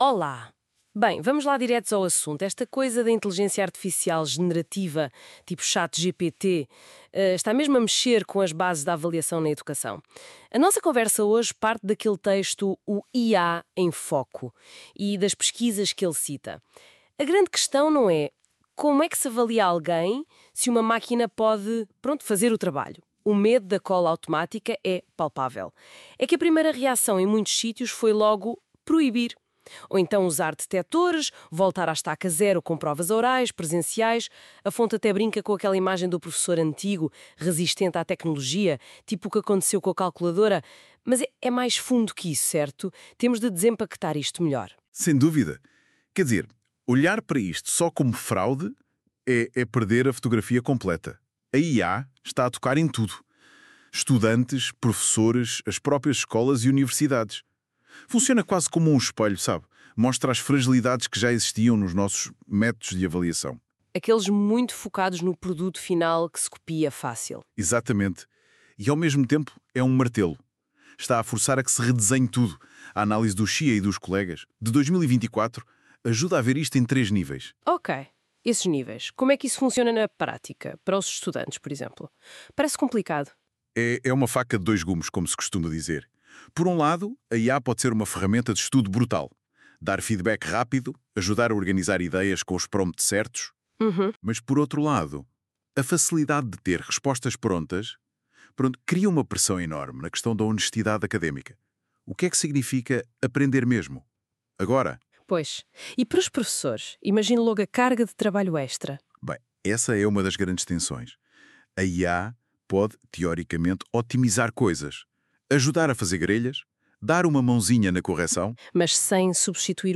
Dito isso, seguem as síntese do texto avaliação em tempos de IA, produzidas pelo NotebookLM.
Resumo em áudio